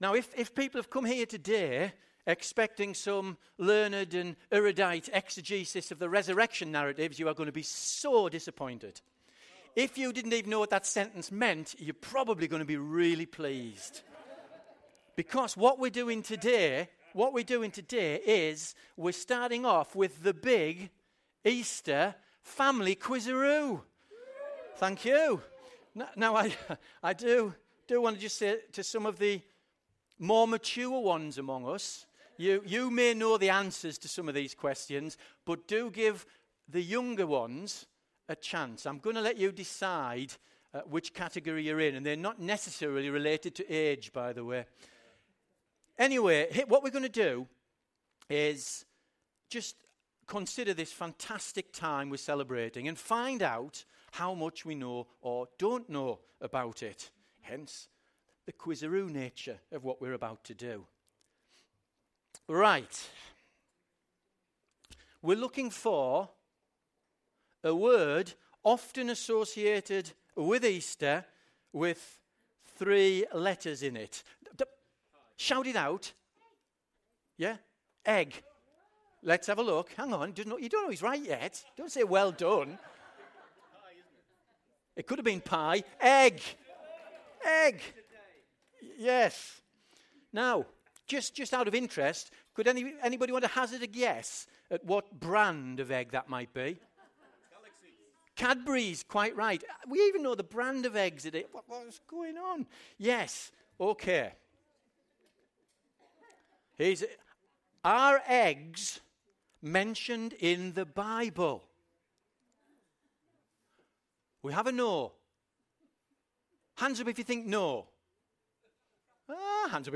Easter Sunday Service 16 04 17